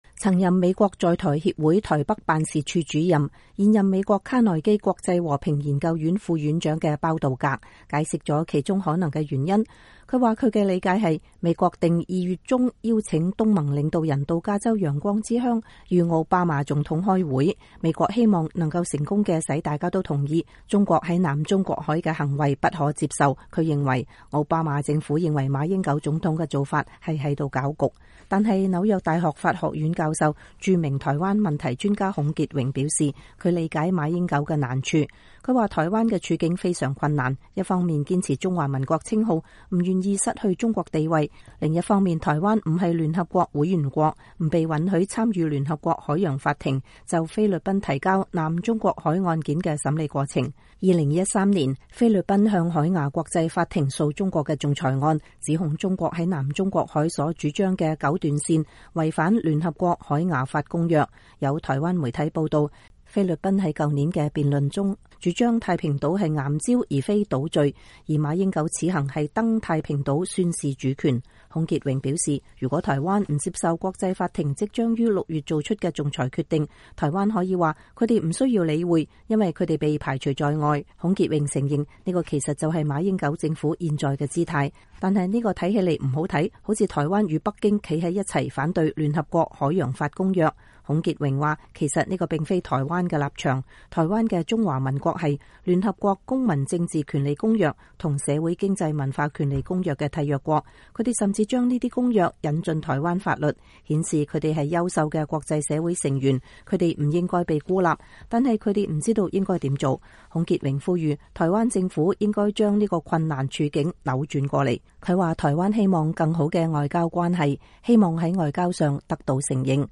兩位重量級台灣問題專家星期三在紐約亞洲協會網絡雜誌《中參館》舉辦的台灣大選後走向的研討會上作上述表示的。